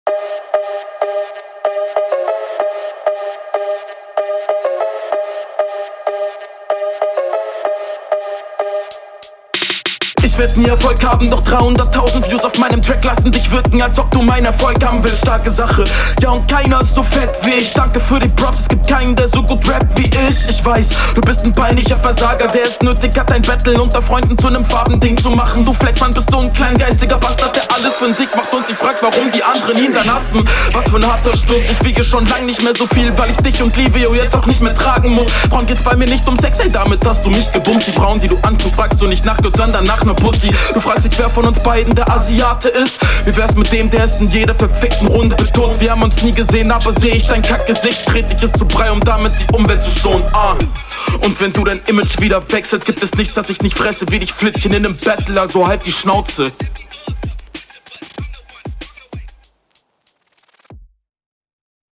Du ballerst flowtechnisch wirklich gut durch Alter!